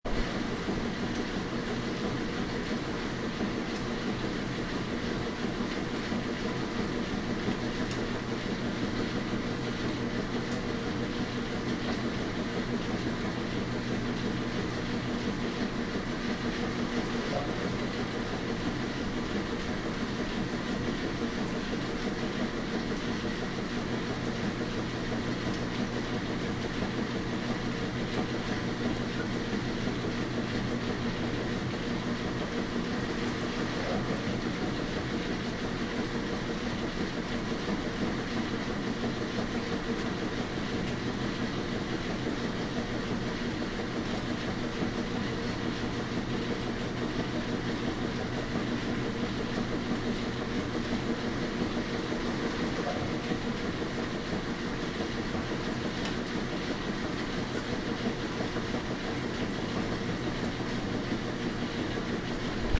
Download Washing Machine sound effect for free.
Washing Machine